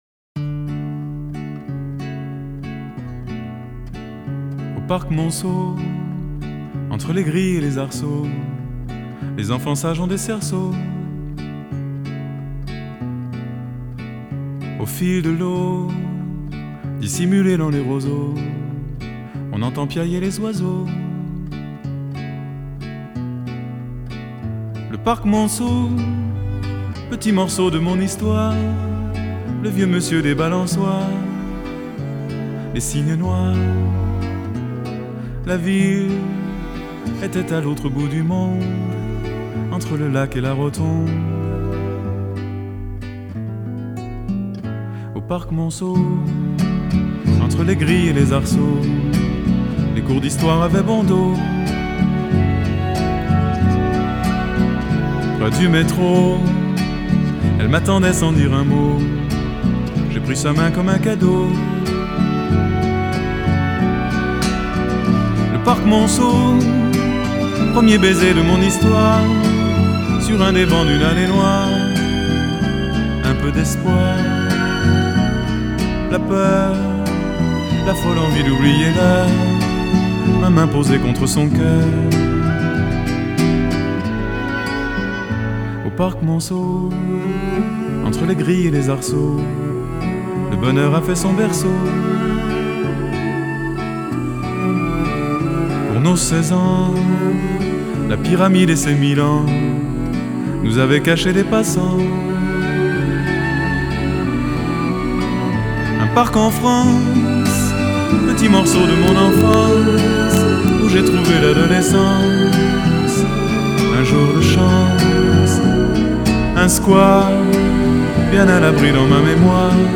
Французская эстрада